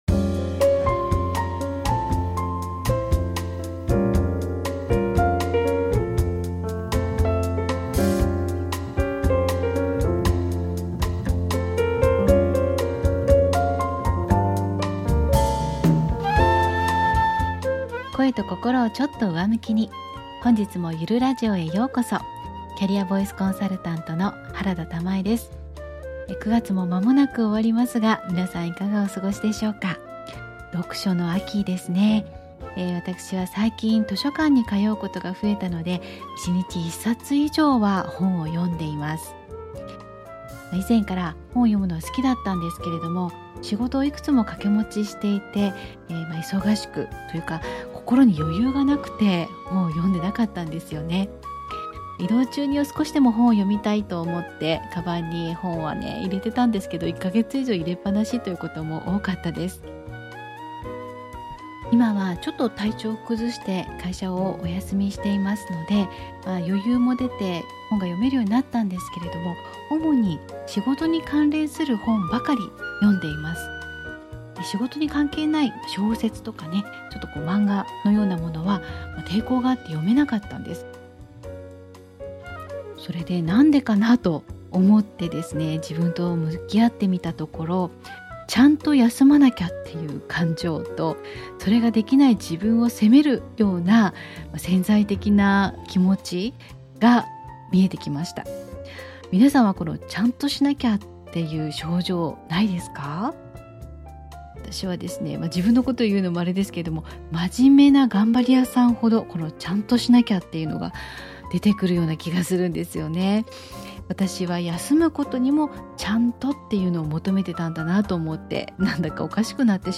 前回に引き続き、猫がそばで戯れ合う中での収録です。
雑音が入っておりますが・・・完璧主義を手放し、